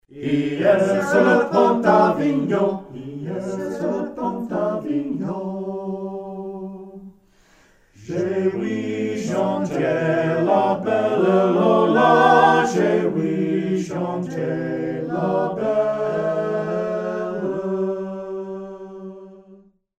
Sung a capella